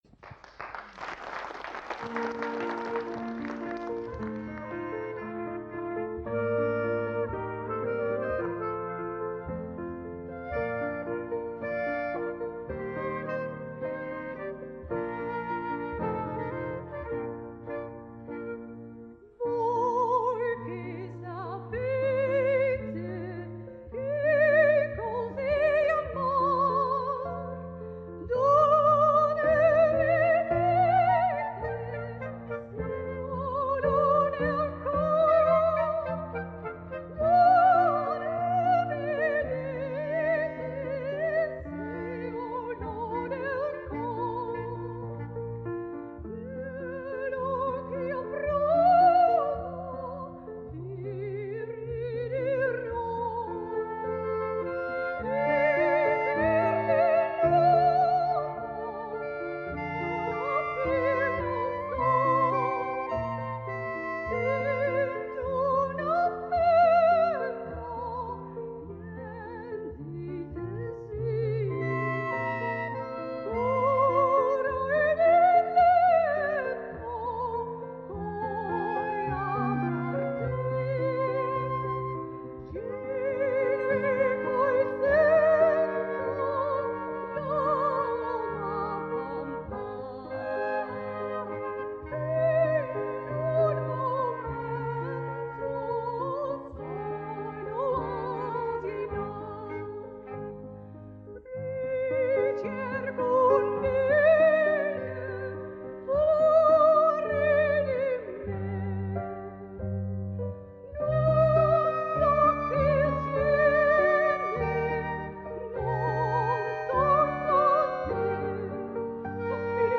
soprano
Hilversum 1 (Dutch broadcasting station),1983
(aria)